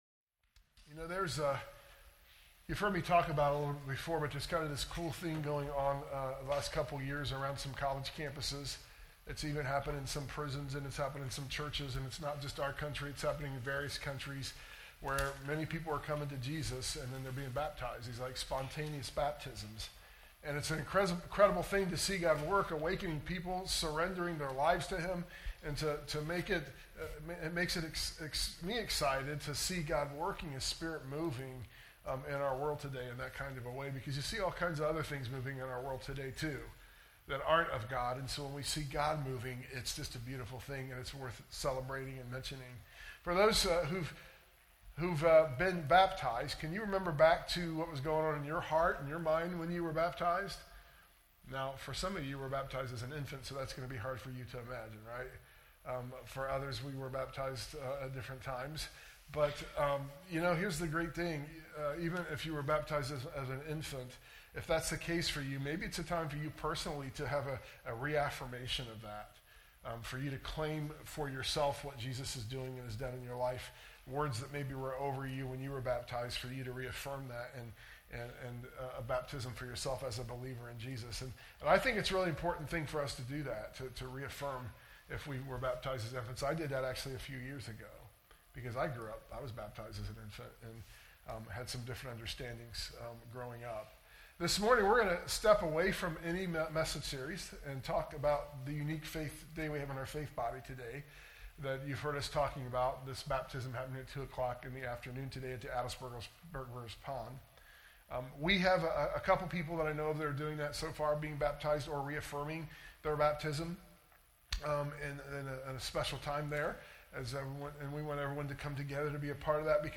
sermon_audio_mixdown_9_7_25.mp3